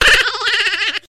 Risata Picchiatello
Risata-Picchiatello.mp3